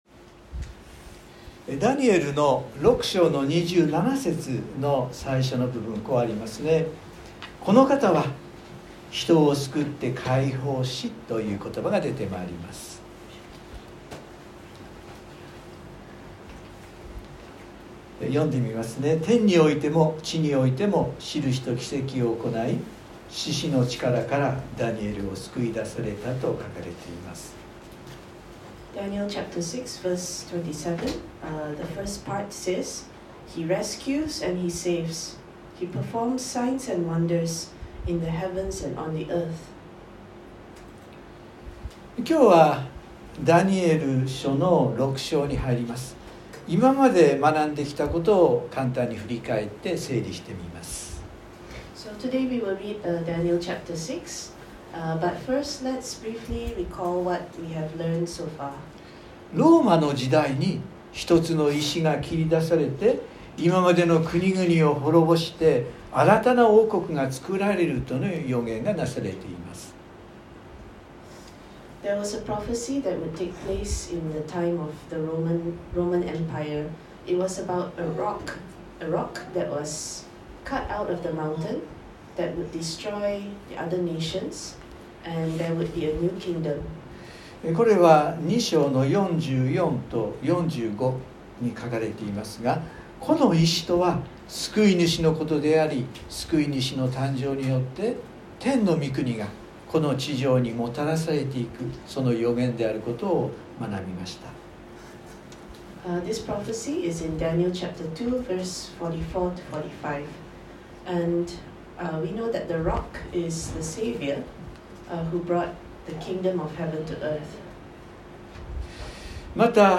（日曜礼拝録音） 【iPhoneで聞けない方はiOSのアップデートをして下さい】 今日はダニエル書６章に入ります。